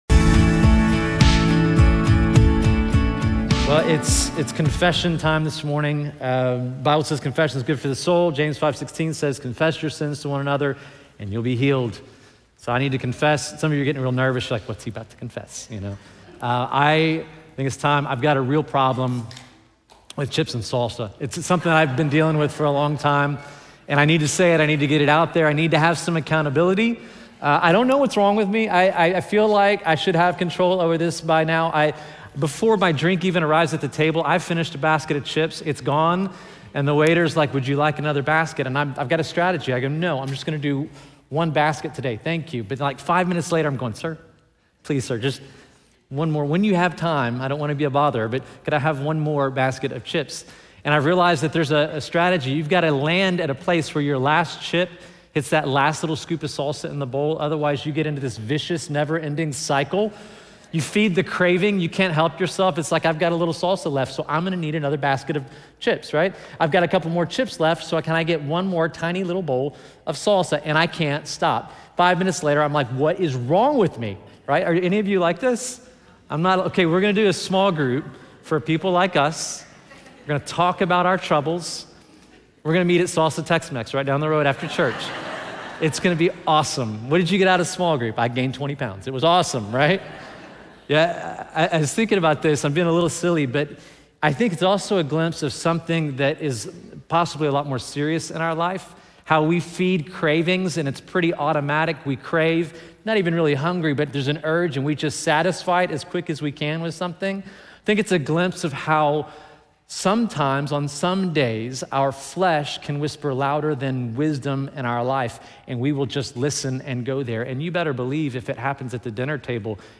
This sermon reminds us that salvation is by grace through faith—Jesus plus nothing equals everything—and that the Holy Spirit lives within every believer, not just to comfort but to lead us into a transformed life.